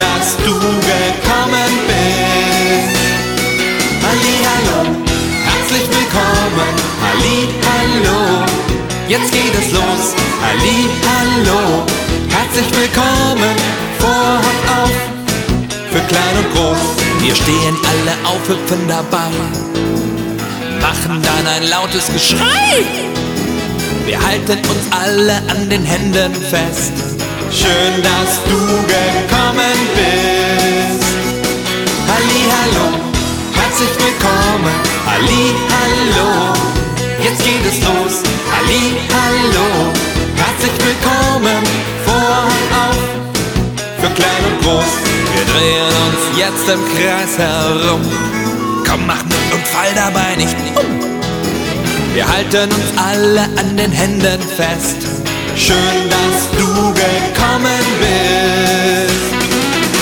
• Sachgebiet: Kinderlieder